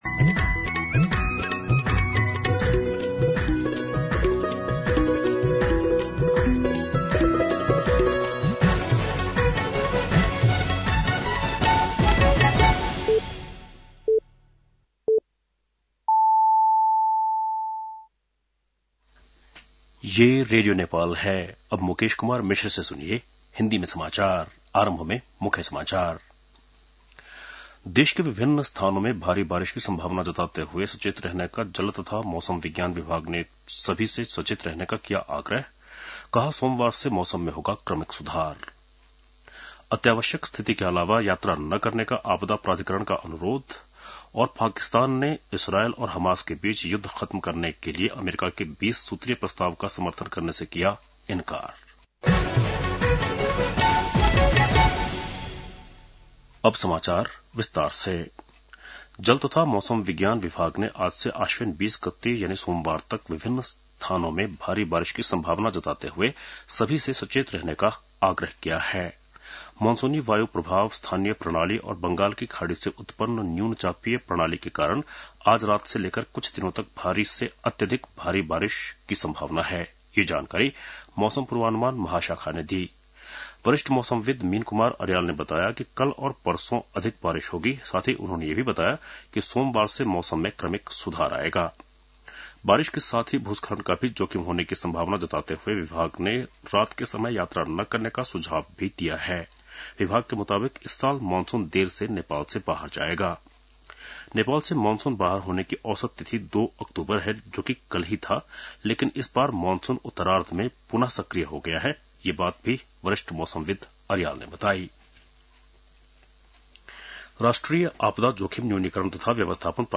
बेलुकी १० बजेको हिन्दी समाचार : १७ असोज , २०८२